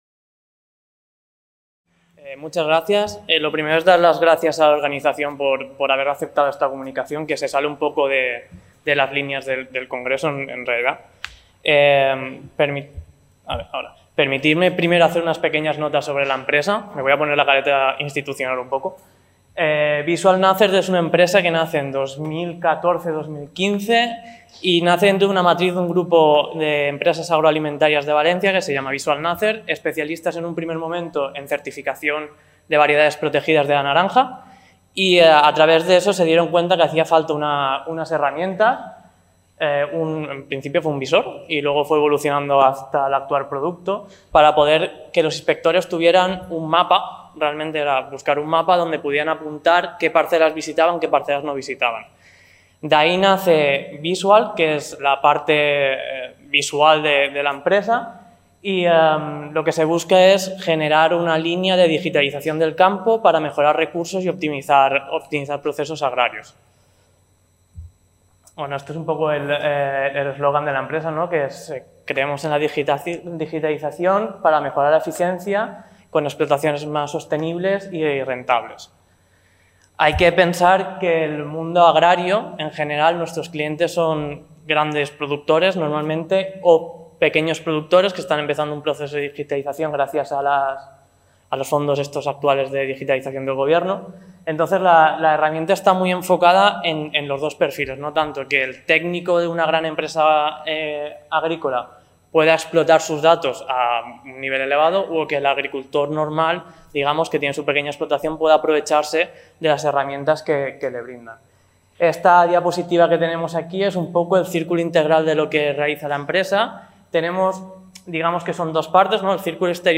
>  En aquesta ponència s'exposa com l'ús de tecnologies basades en l'anàlisi espacial com les imatges satèlit i els SIG són peces clau per optimitzar la utiització dels recursos que aplica el sector agrícola en les seves tasques diàries. L'agricultura de precisió es basa en aquestes tecnologies per tal d'obtenir major productivitat, rentabilitat i sostenibilitat  Aquest document està subjecte a una llicència Creative Commons: Reconeixement – No comercial – Compartir igual (by-nc-sa) Mostra el registre complet de l'element